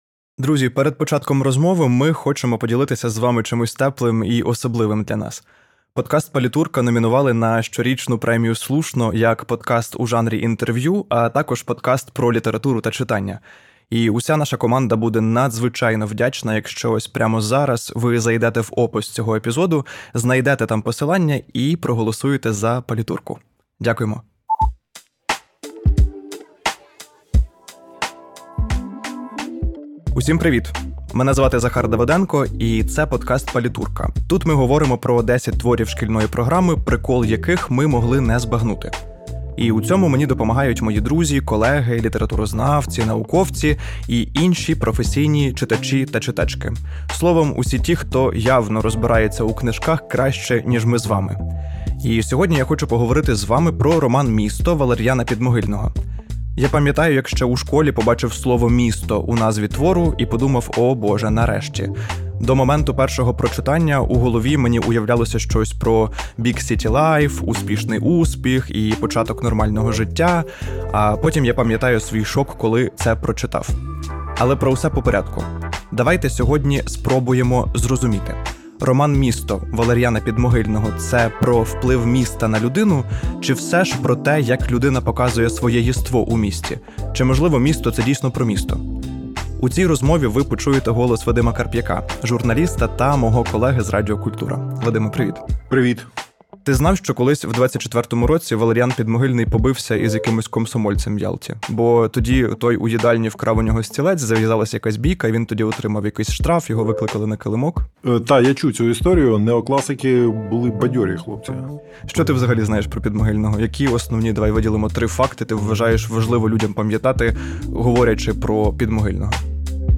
9: Як вижити у столиці? | Розмова про «Місто»